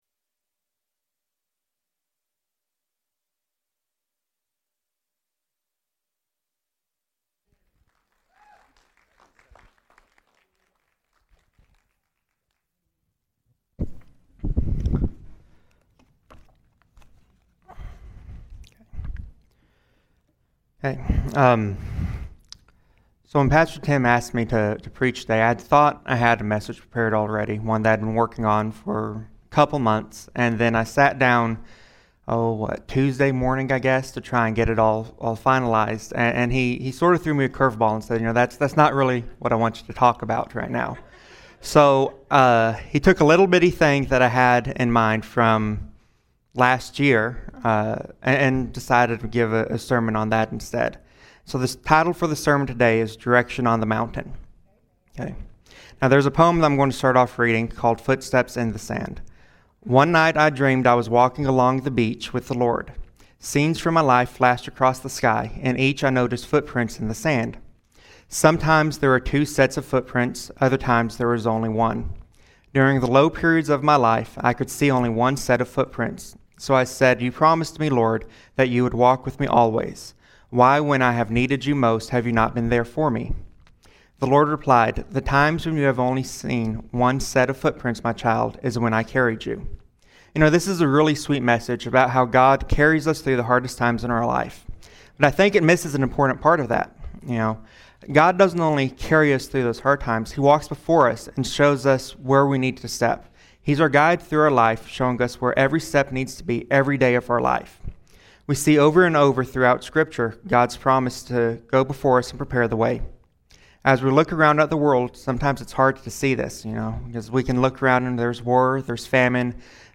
Freedom Life Fellowship Live Stream